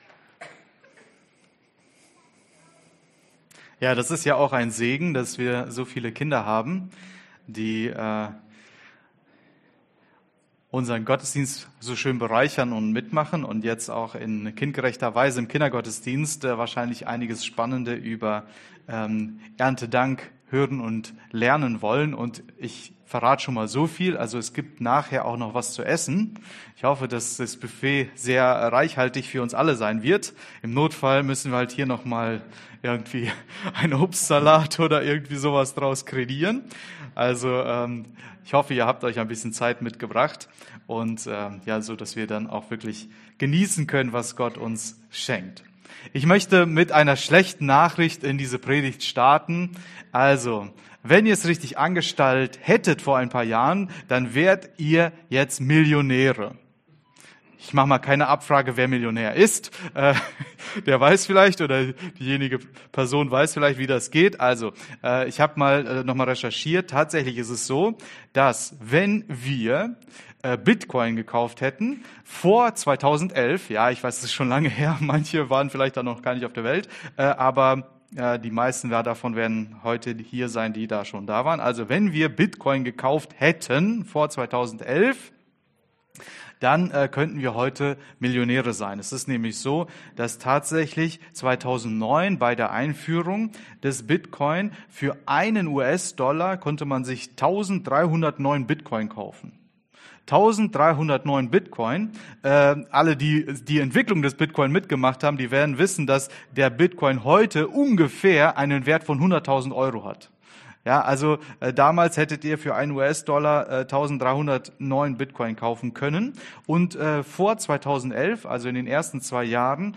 werden wir ernten Prediger